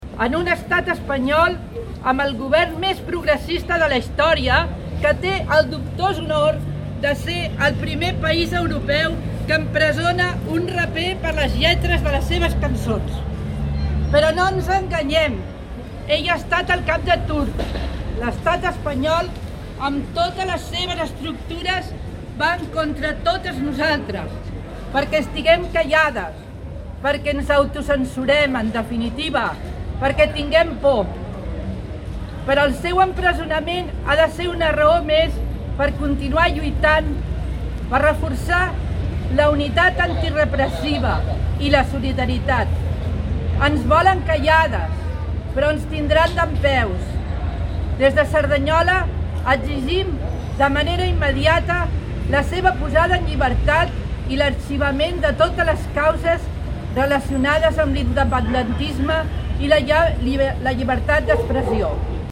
Més d'un centenar de persones es concentraven al crit de Llibertat Pablo Hasél davant la porxada de l'Ajuntament de Cerdanyola contra l'empresonament del músic de rap condemnat a nou mesos de presó, sis anys d'inhabilitació i prop de 30.000 euros de multa per delictes d'enaltiment del terrorisme i injúries contra la Corona i institucions de l'Estat.
Fragment del manifest llegit